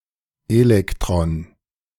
Das Elektron (IPA: [ˈeːlɛktrɔn][3],
In der deutschen Aussprache kann wahlweise auch die zweite oder dritte Silbe betont sein.